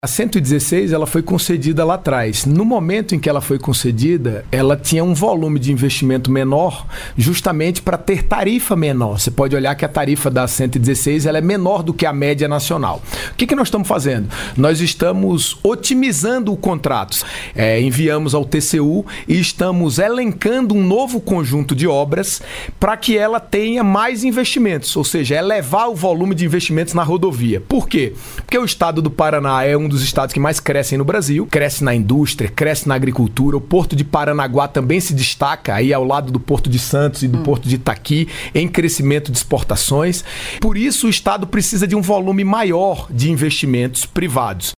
SONORA-MINISTRO-TRANSPORTES-01-CS.mp3